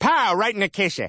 Worms speechbanks